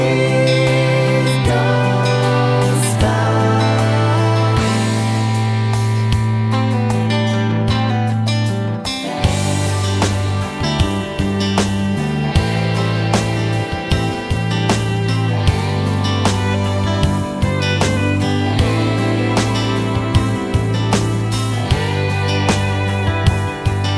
Full Version Country (Male) 4:09 Buy £1.50